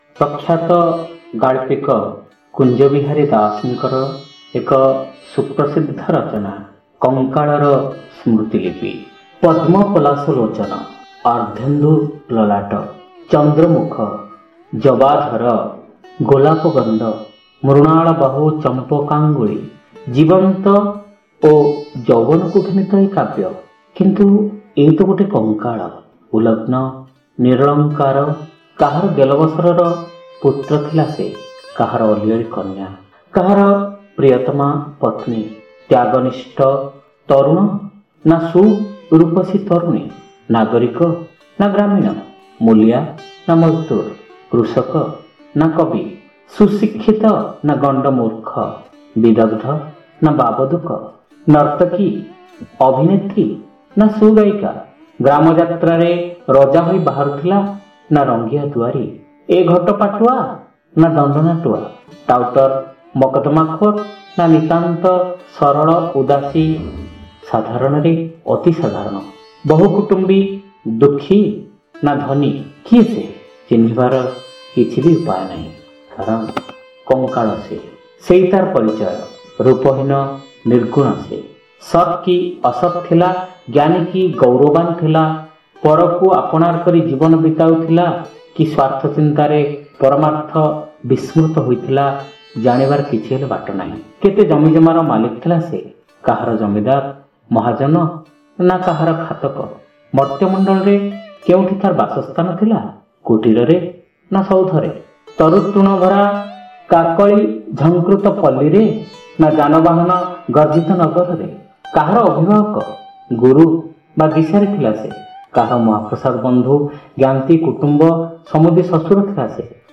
ଶ୍ରାବ୍ୟ ଗଳ୍ପ : କଙ୍କାଳର ସ୍ମୃତିଲିପି